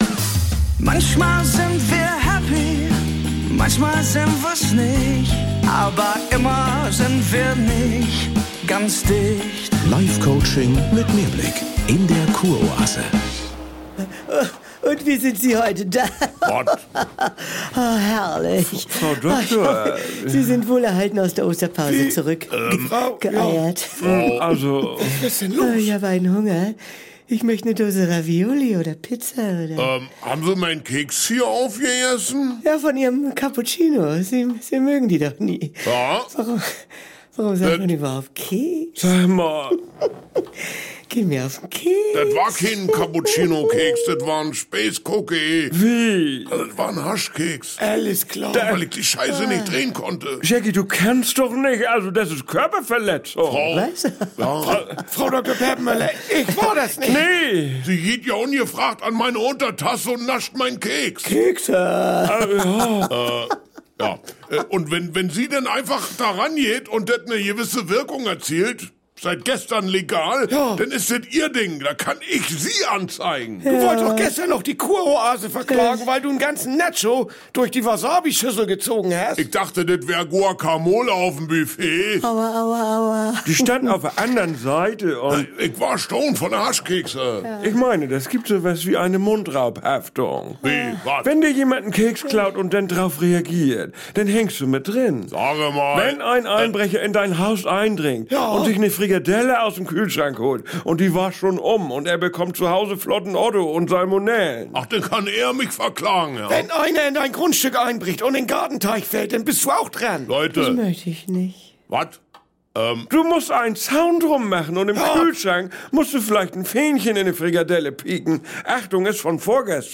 Komödie NDR